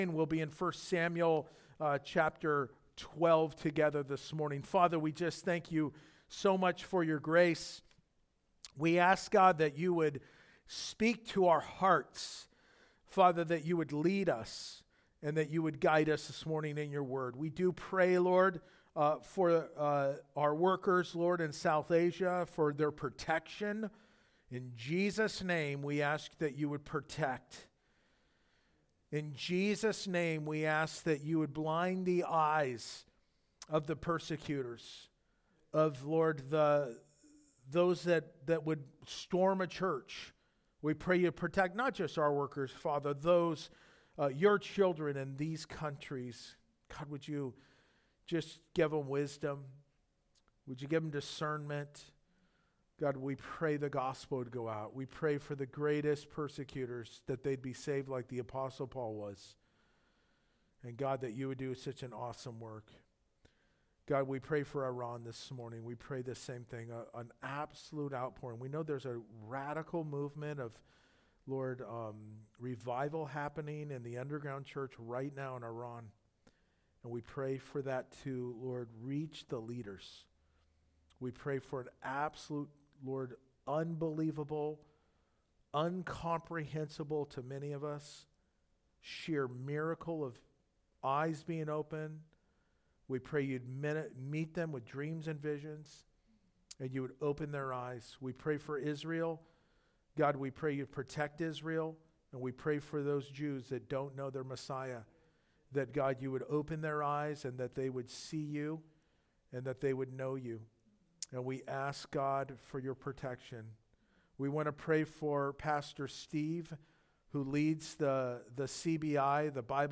Is+God+Really+Faithful+2nd+Service.mp3